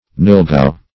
Nilgau \Nil"gau\, n. (Zool.)